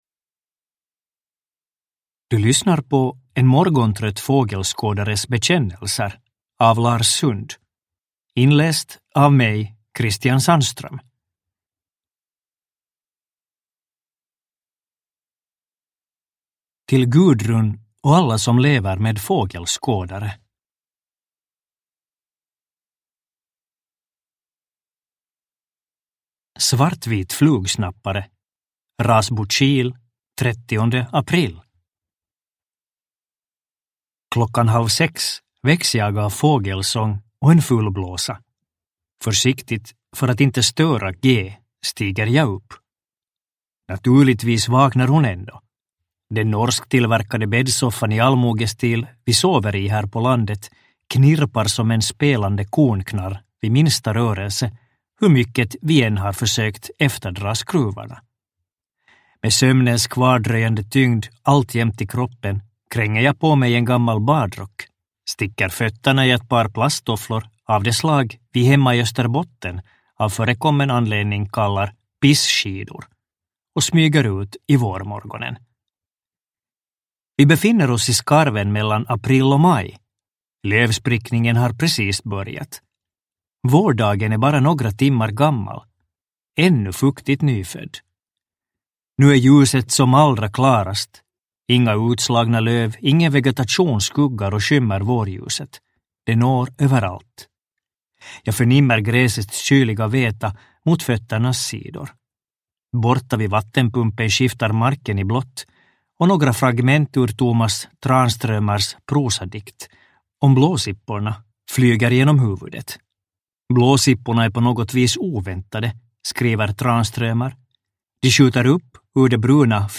En morgontrött fågelskådares bekännelser – Ljudbok – Laddas ner